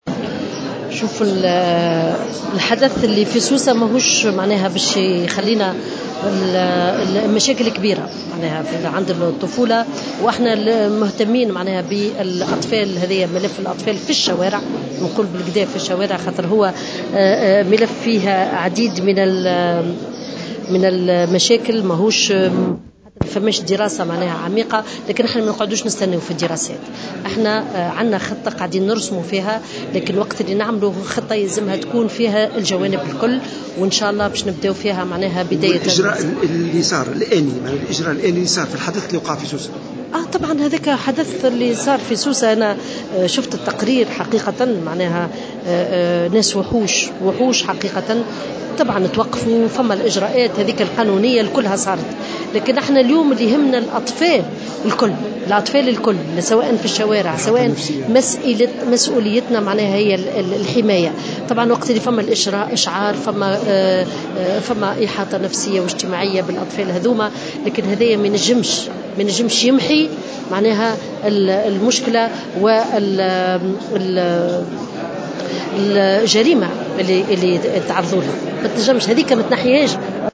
وقالت الوزيرة في تصريح لمراسل "الجوهرة أف أم" على هامش زيارة قامت بها إلى قابس إنه تم اتخاذ الإجراءات القانونية اللازمة من طرف السلطات المعنية وإن وزارتها قد شرعت في إعداد خطة وطنية لتفادي حدوث مثل هذه الجرائم الفظيعة في حق الأطفال.